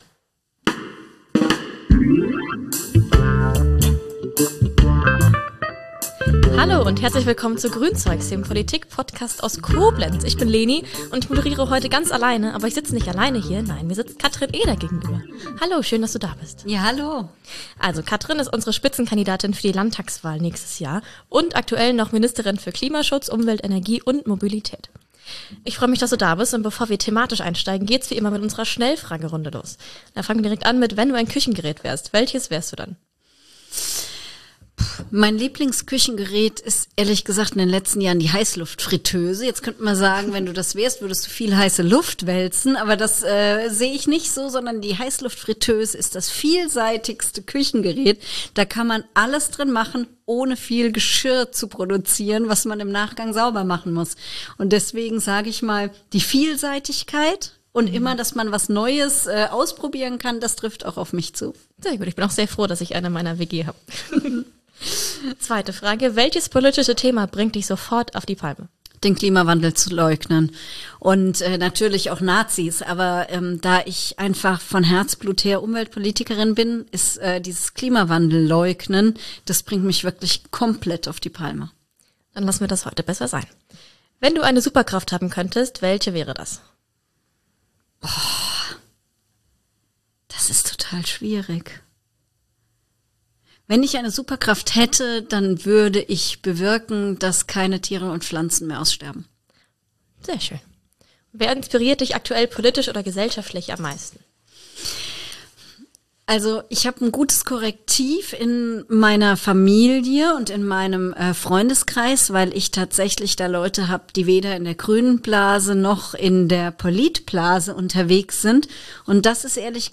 Ist unsere Gesellschaft im Rückwärtsgang? Im Gespräch mit Katrin Eder ~ Grünzeugs Podcast